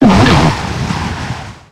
Cri de Boréas dans Pokémon X et Y.